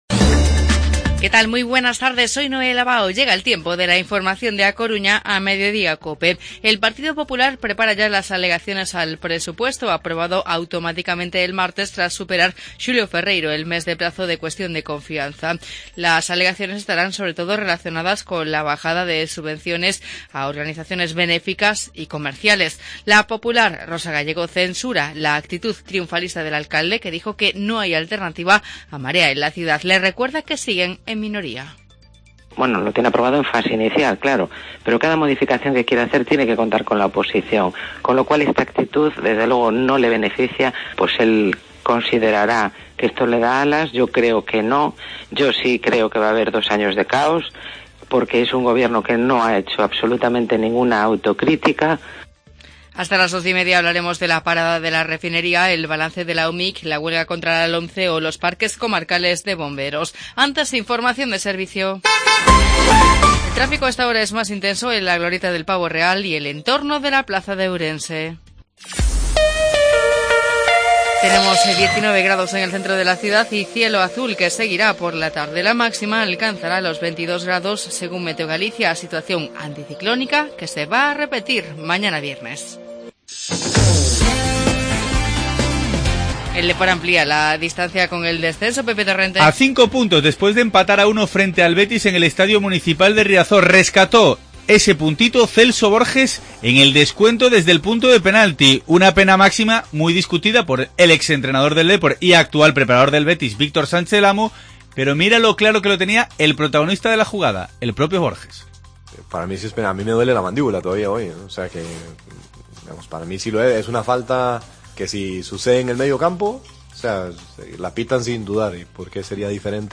Informativo Mediodía COPE Coruña jueves 9 de marzo de 2017